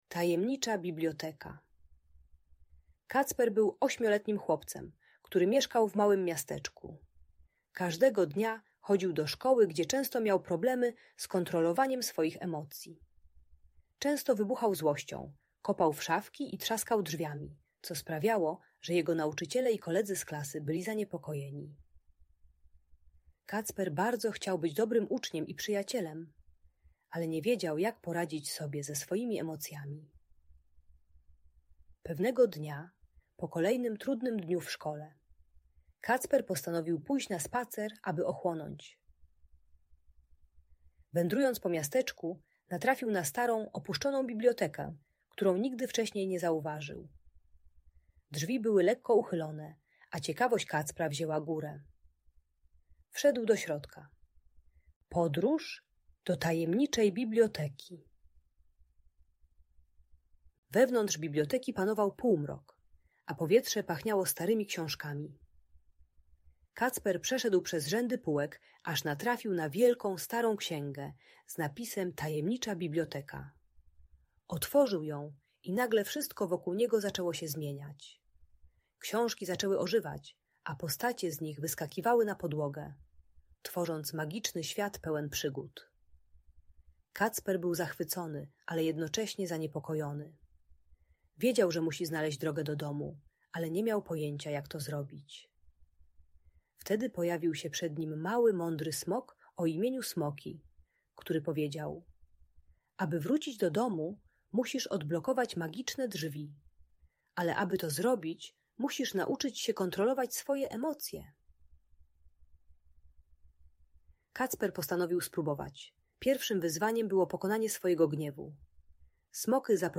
Tajemnicza Biblioteka: Magiczna story o emocjach - Audiobajka dla dzieci